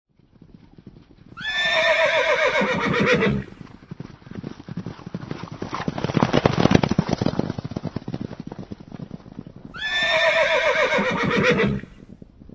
Caballo relinchando y galopando: Efectos de sonido misterio y suspenso
Categoría: Efectos de Sonido
Efecto de sonido que reproduce el relincho característico de un caballo, acompañado del sonido de sus cascos al galopar.
caballa relinchando y galopando.mp3